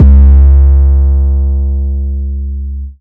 808 Kick 30_DN.wav